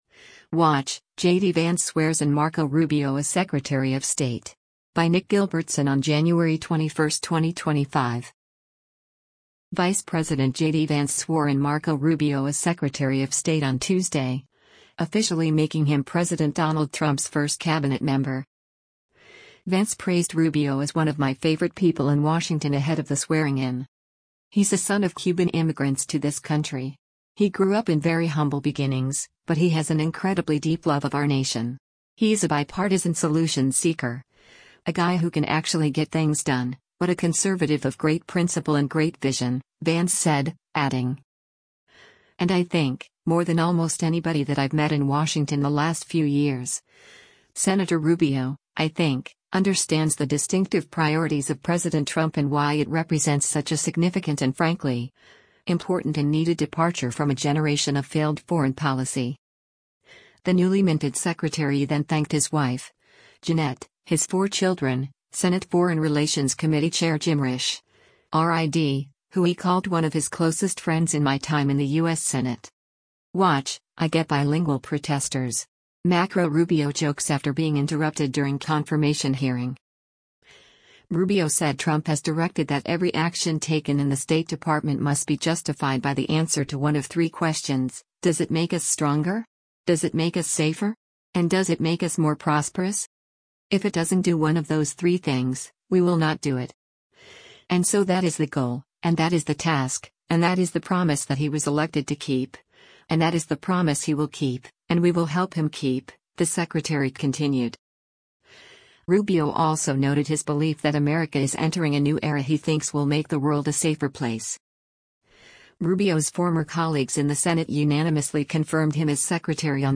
Watch: JD Vance Swears in Marco Rubio as Secretary of State
Vance praised Rubio as “one of my favorite people in Washington” ahead of the swearing-in.